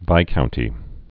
(vīkountē)